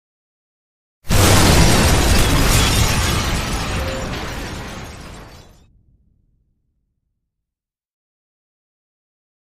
Explosion Heavy Glass Destruction Type 1 Version 1